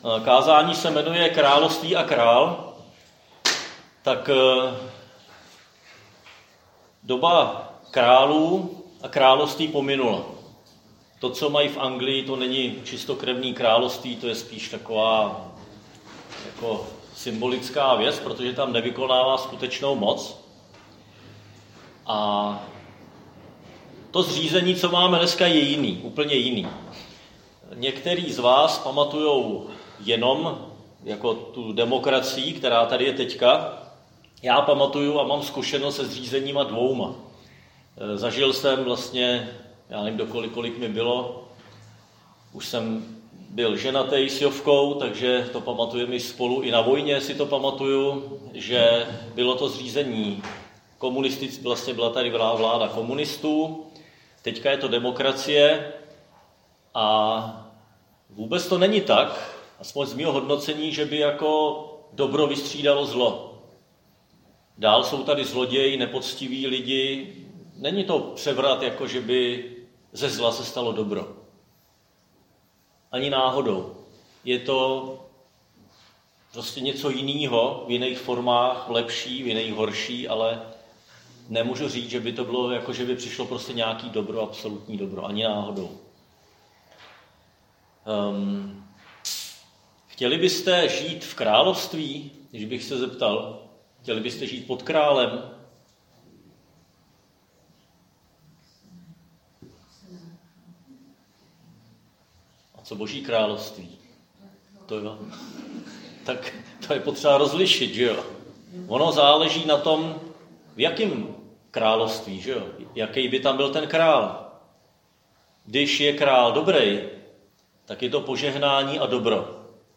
Křesťanské společenství Jičín - Kázání 31.10.2021